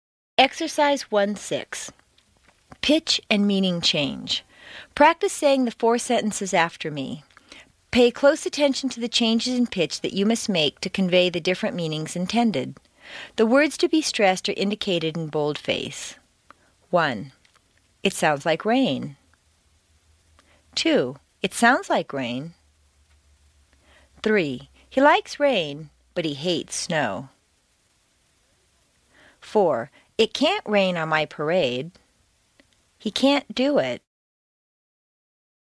美式英语正音训练第10期:音高和语义的变化 听力文件下载—在线英语听力室
在线英语听力室美式英语正音训练第10期:音高和语义的变化的听力文件下载,详细解析美式语音语调，讲解美式发音的阶梯性语调训练方法，全方位了解美式发音的技巧与方法，练就一口纯正的美式发音！